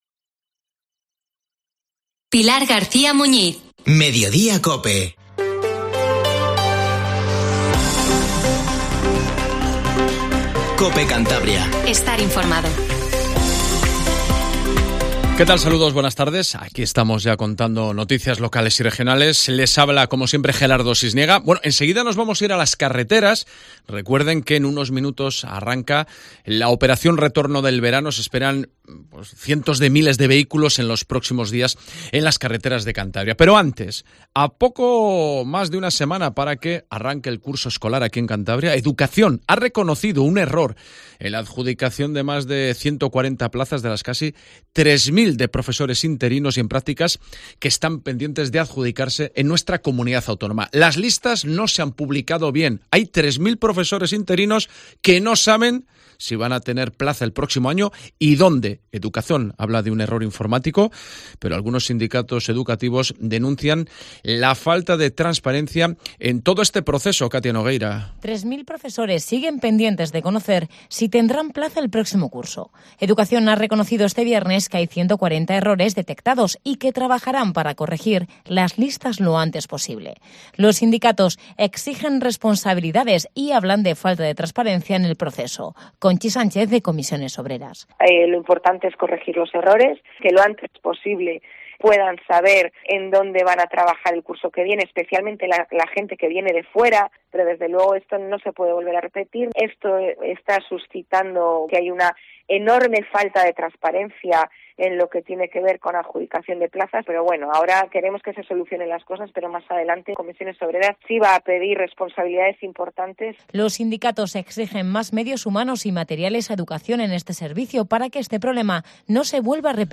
Informativo Regional 1420